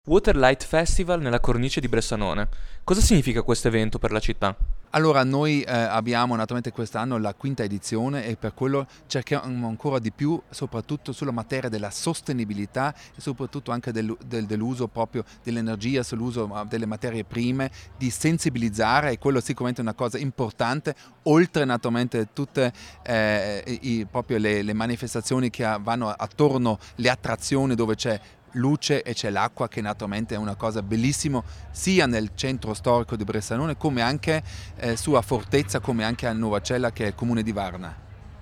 Ecco le dichiarazioni raccolte durante la serata di inaugurazione:
Peter Brunner, sindaco di Bressanone